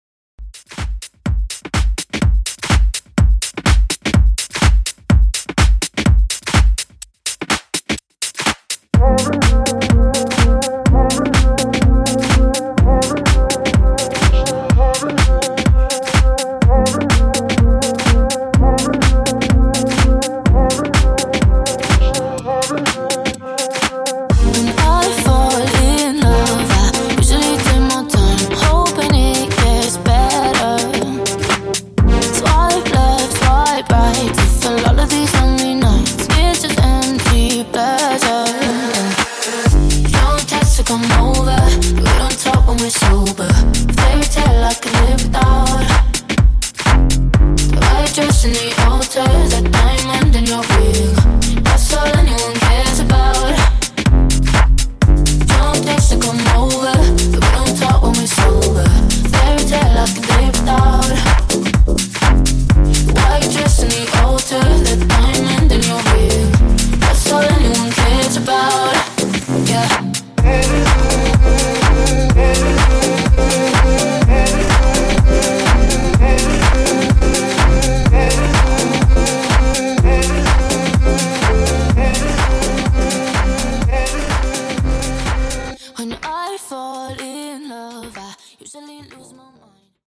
Club House)Date Added